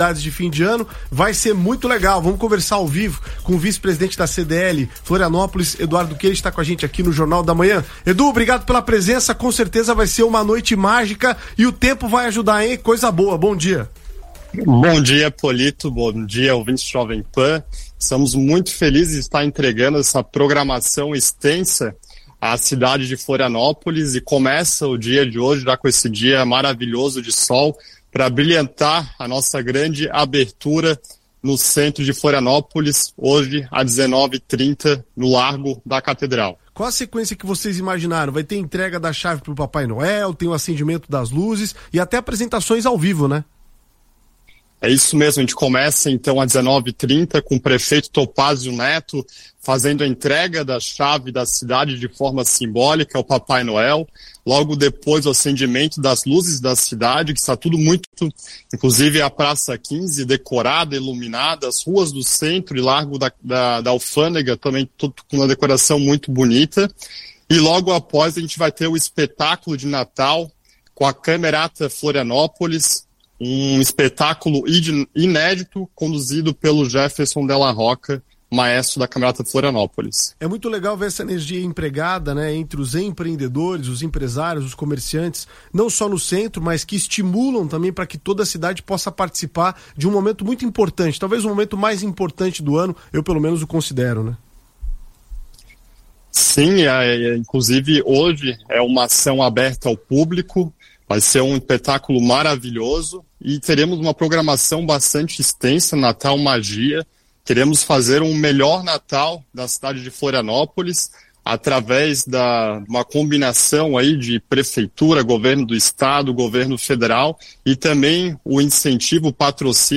Rádio: Jovem Pan/Florianópolis